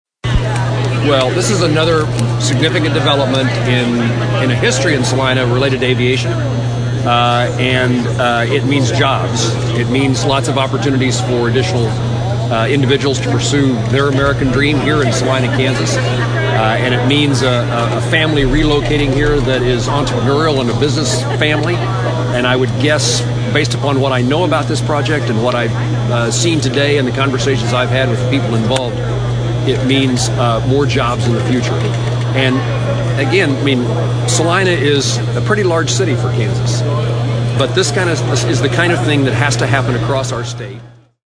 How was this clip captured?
An aviation business with a vision in Salina opened up shop on Friday with a ribbon cutting ceremony–and with that brings highly skilled jobs with excellent pay.